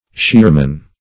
shearman - definition of shearman - synonyms, pronunciation, spelling from Free Dictionary Search Result for " shearman" : The Collaborative International Dictionary of English v.0.48: Shearman \Shear"man\, n.; pl. Shearmen . One whose occupation is to shear cloth.